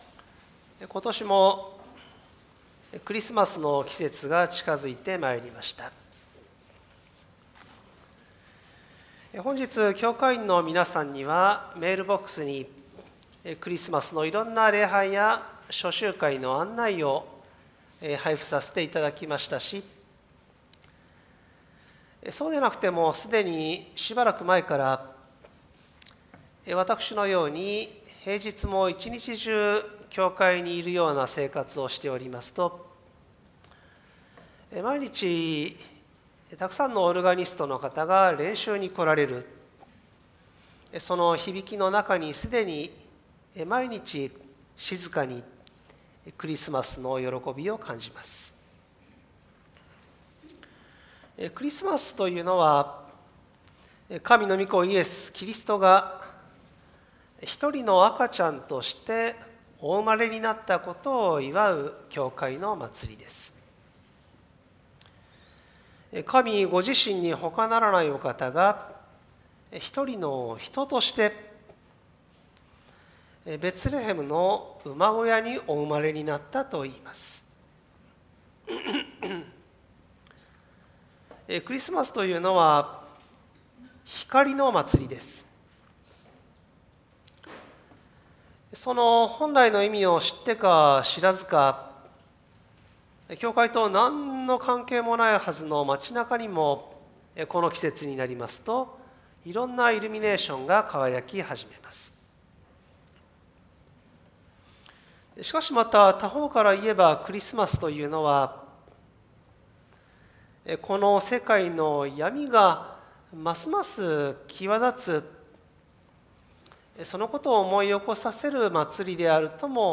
１９１７年伝道開始のプロテスタント教会、ＪＲ鎌倉駅から最も近い場所にある教会です。
主日礼拝